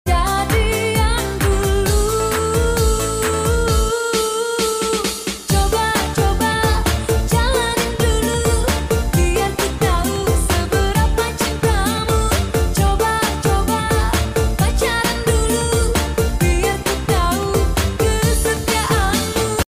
ya gimana lagi motor pelan sound effects free download